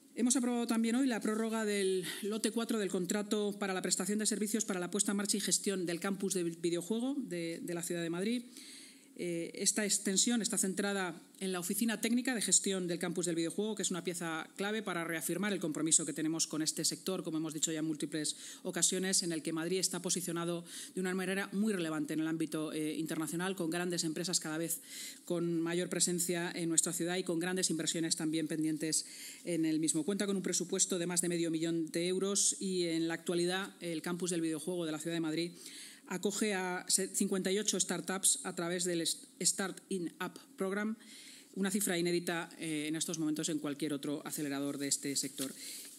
Nueva ventana:La vicealcaldesa de Madrid y portavoz municipal, Inma Sanz